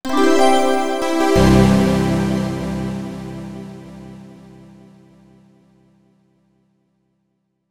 Positive Synth.wav